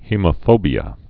(hēmə-fōbē-ə)